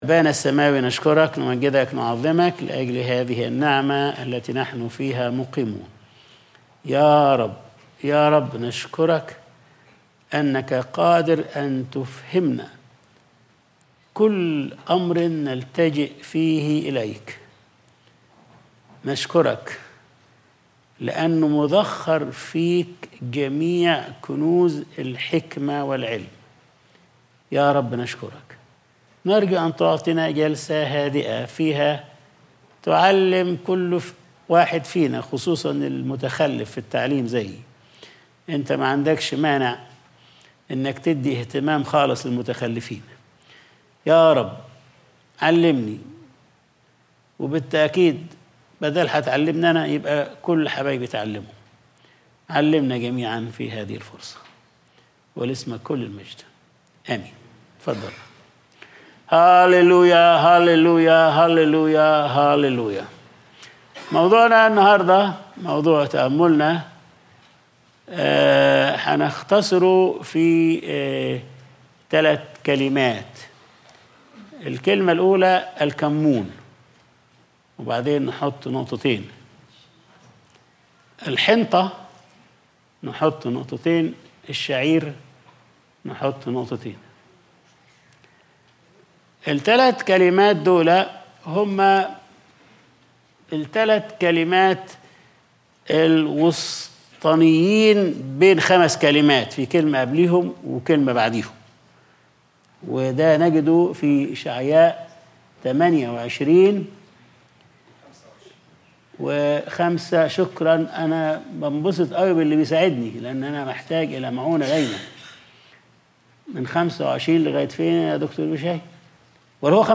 Sunday Service | الكمُّون ..